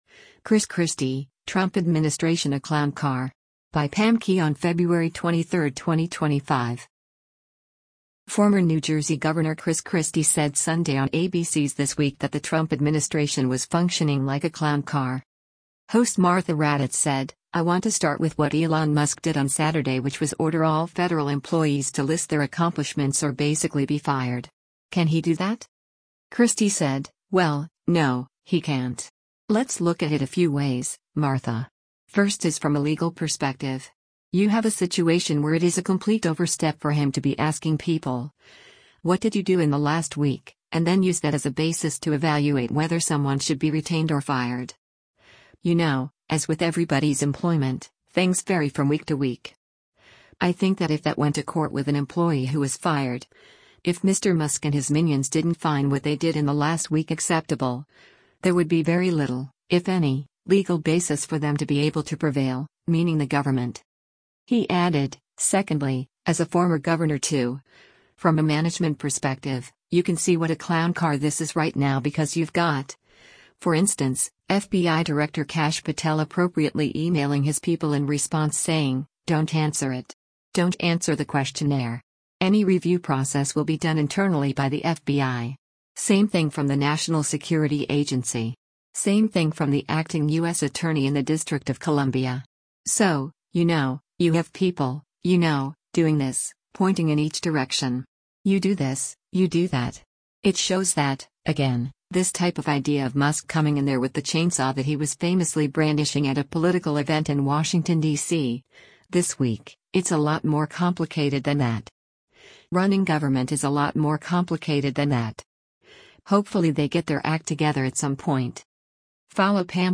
Former New Jersey Gov. Chris Christie said Sunday on ABC’s “This Week” that the Trump administration was functioning like a “clown car.”